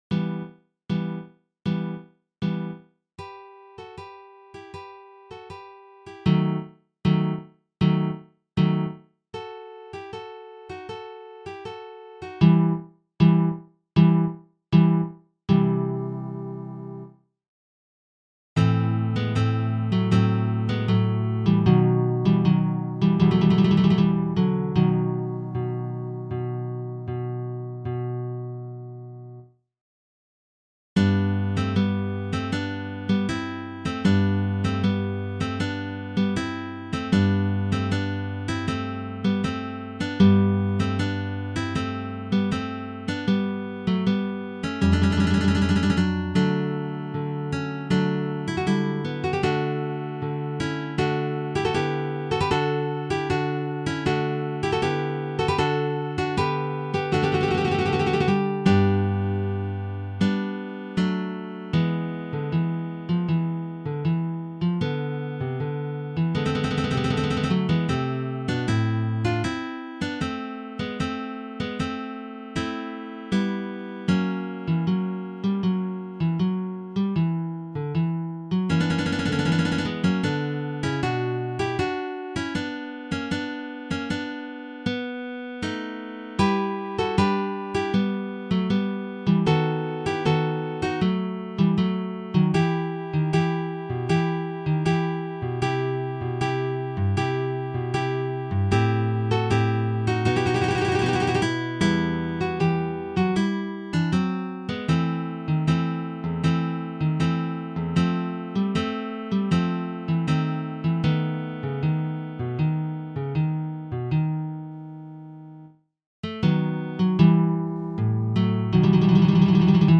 Arranged for Guitar Trio